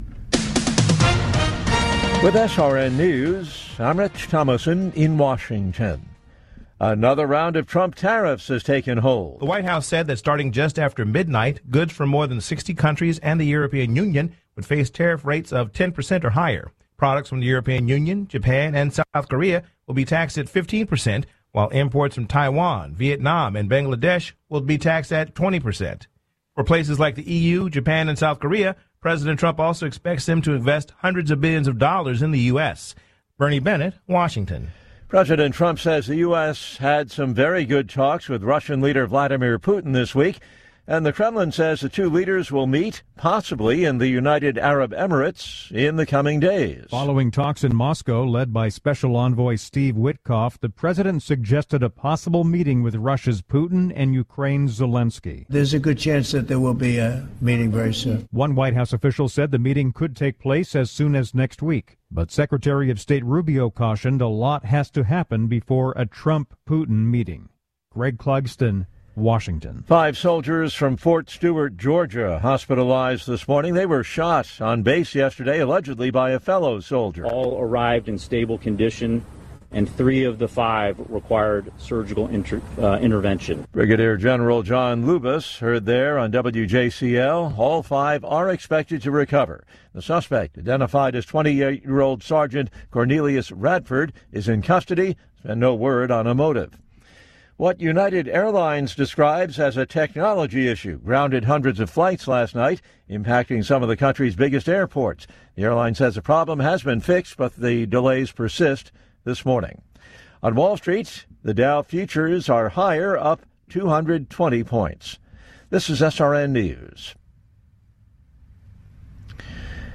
Latest news stories from around the world brought to you at the top of the hour